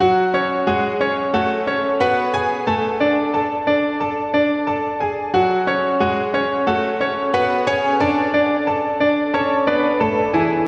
Tag: 90 bpm Hip Hop Loops Piano Loops 1.80 MB wav Key : F